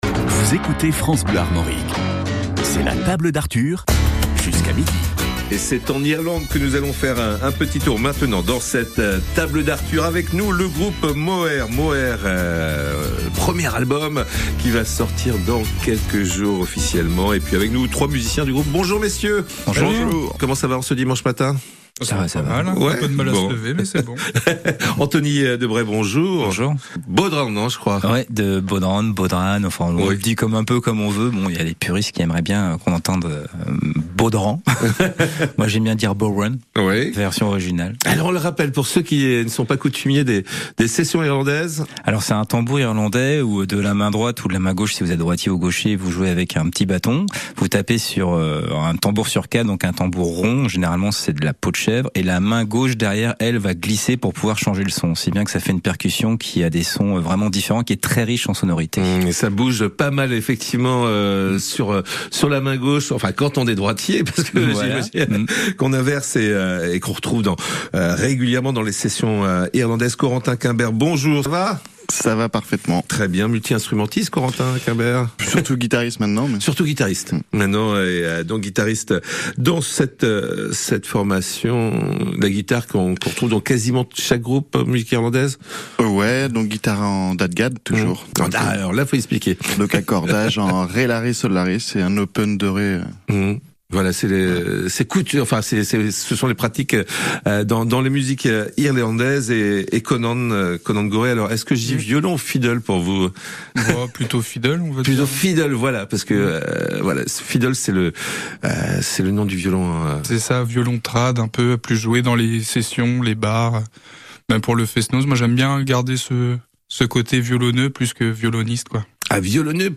Musique irlandaise musique du monde